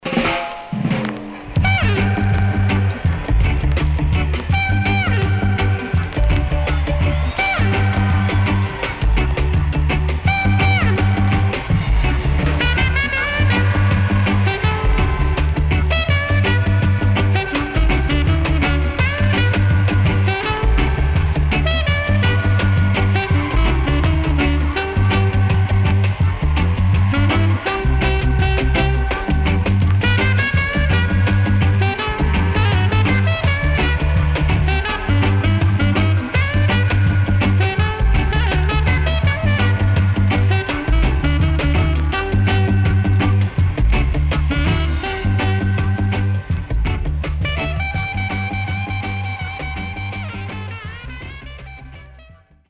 Great Early Reggae Vocal & Inst.Skinheads.W-Side Great!!
SIDE A:うすいこまかい傷ありますがノイズあまり目立ちません。